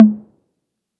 808 H Tom 1.wav